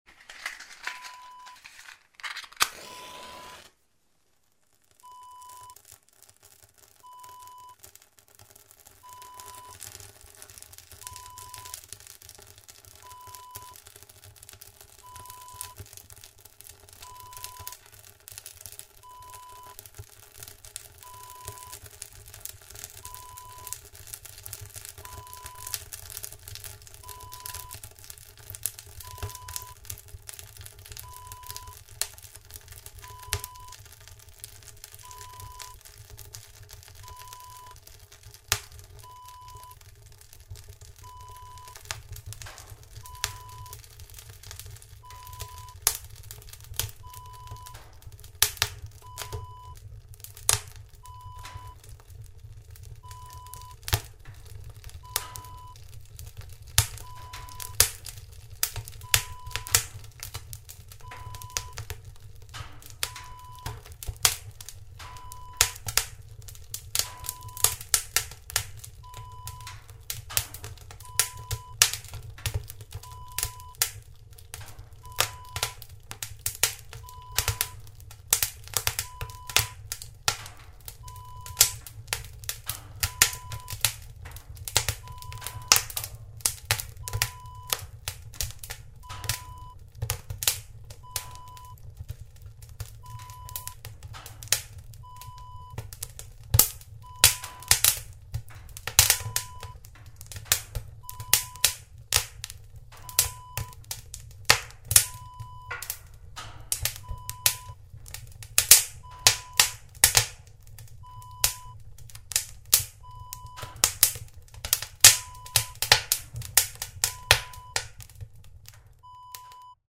Wood Burning Stove Heater Fire Lit Up Wav Sound Effect
Description: The sound of lighting a fire in a wood burning stove heater
Properties: 48.000 kHz 24-bit Stereo
A beep sound is embedded in the audio preview file but it is not present in the high resolution downloadable wav file.
wood-burning-stove-fire-lit-up-preview-1.mp3